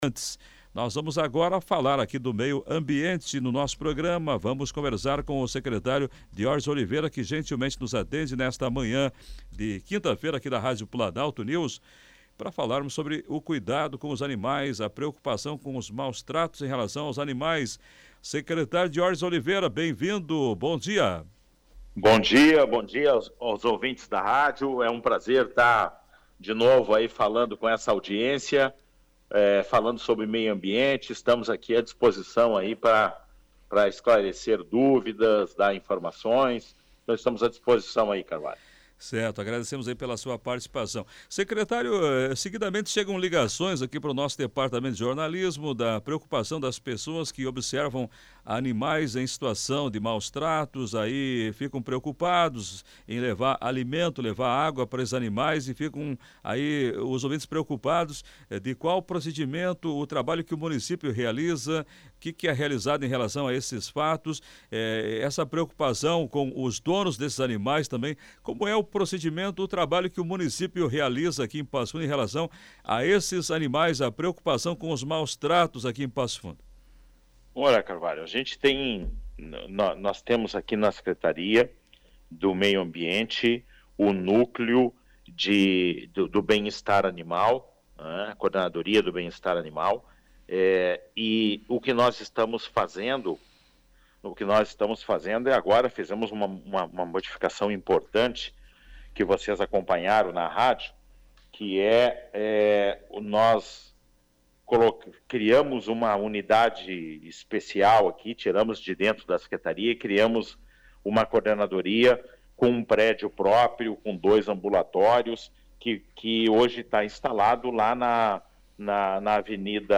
Para os esclarecimentos, foi realizada entrevista com o secretário municipal do Meio Ambiente de Passo Fundo, Diorges Oliveira. Foram apresentado os investimentos que a Prefeitura de Passo Fundo vem realizando na proteção dos animais e ao mesmo tempo a busca pelo melhor encaminhamento quando são verificadas situações de maus-tratos, a partir das denúncias da população.